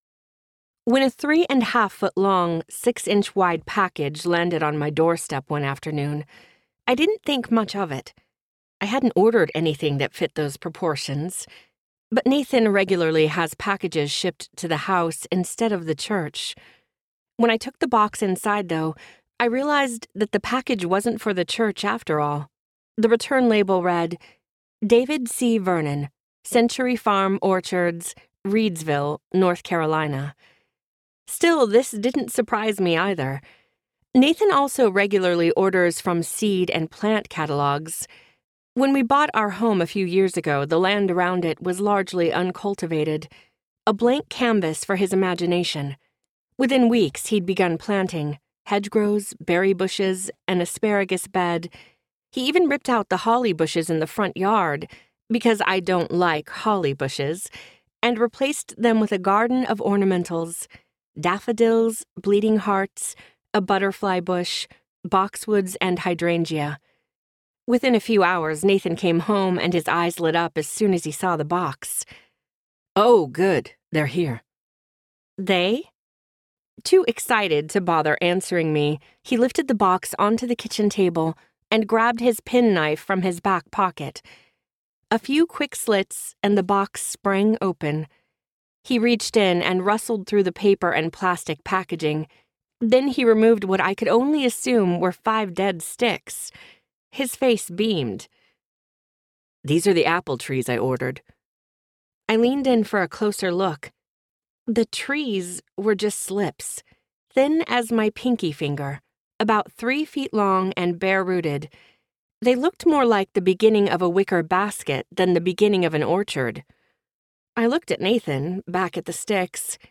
Humble Roots Audiobook
5.67 Hrs. – Unabridged